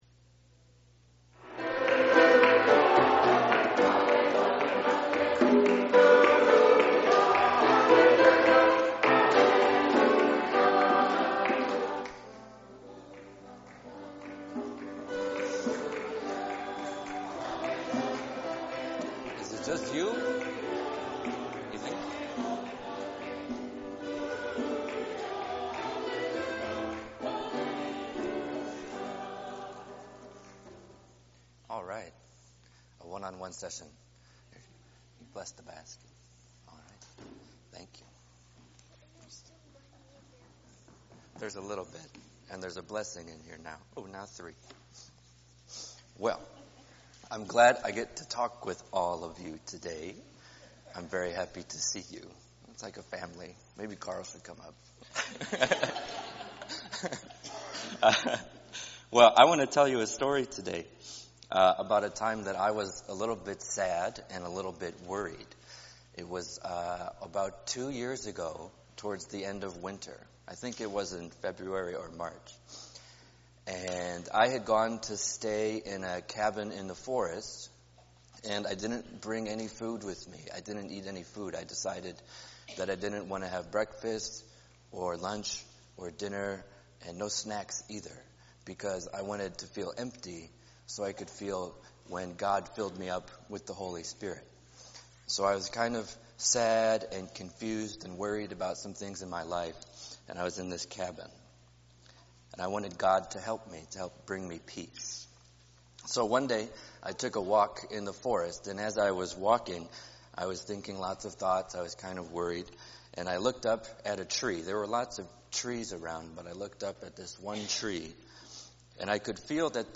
The beginning and the very end of the service got cut off, but the message is all there!